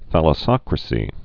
(thălə-sŏkrə-sē)